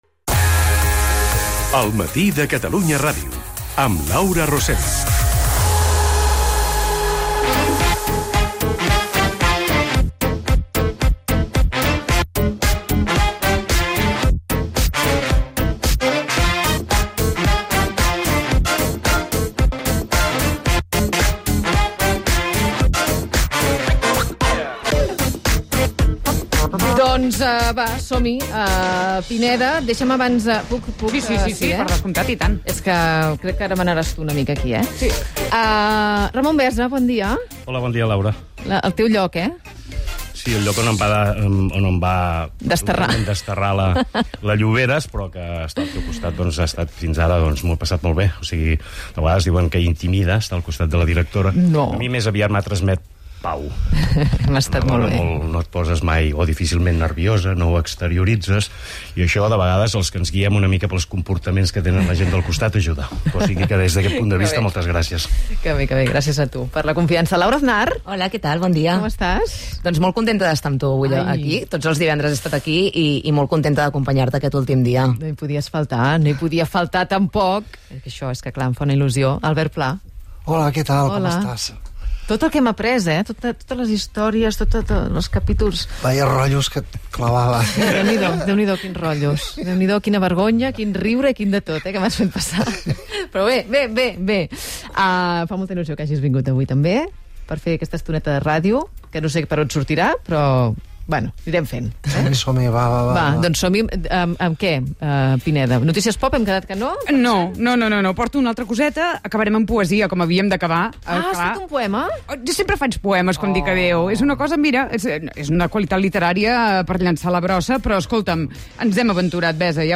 Tertúlia sobre el fet al programa.